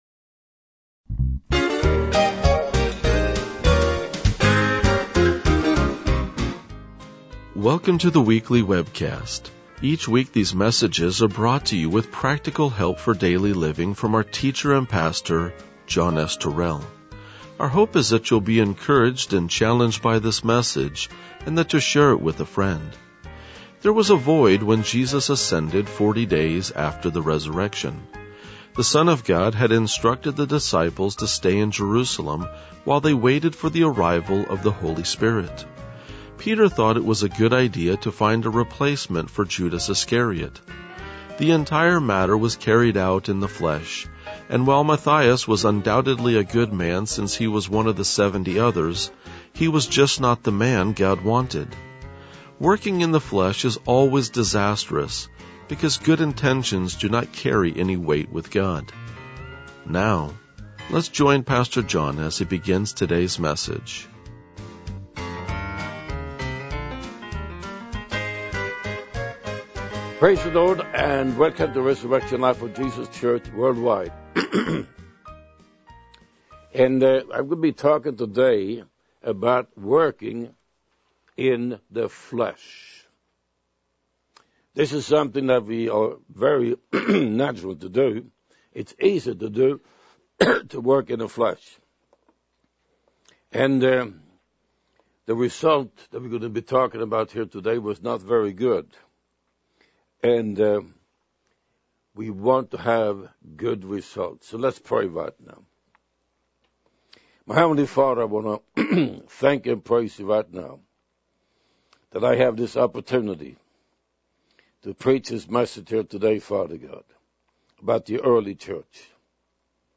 RLJ-1998-Sermon.mp3